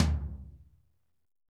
TOM P C L0WR.wav